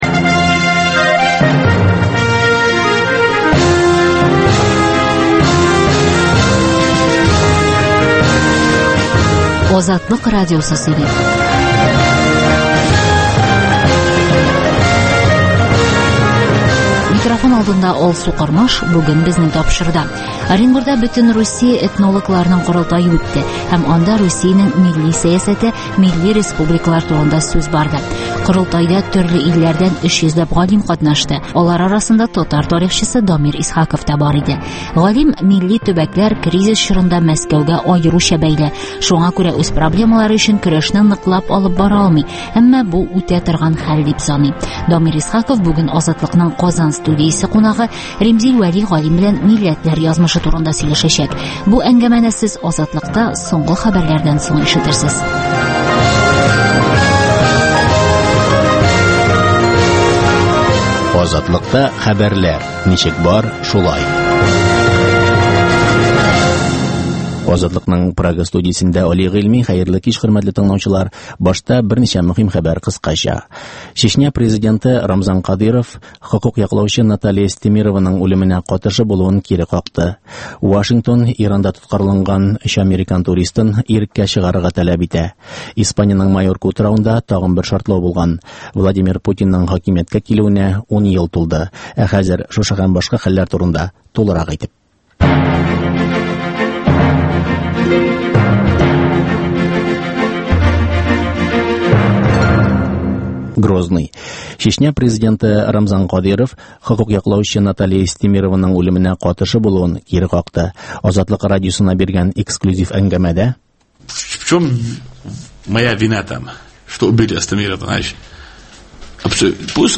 Азатлык узган атнага күз сала - соңгы хәбәрләр - түгәрәк өстәл сөйләшүе